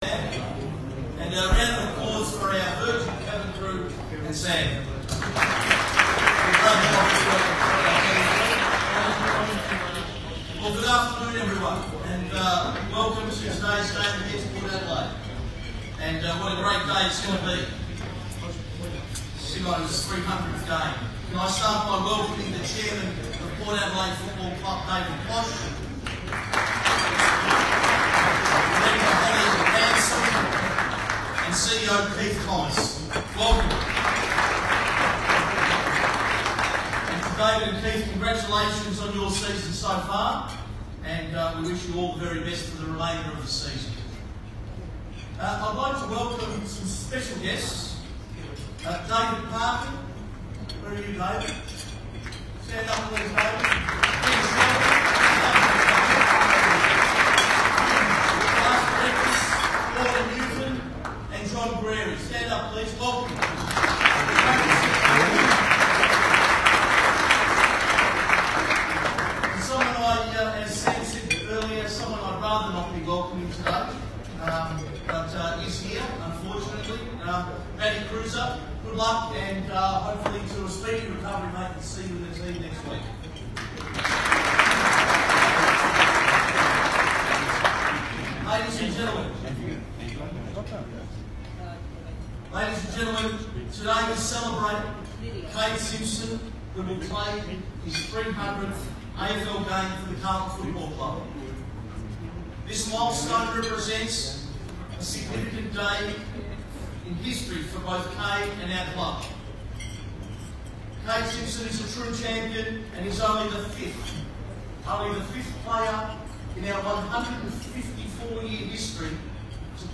President's speech | Round 15